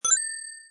coin_bling.mp3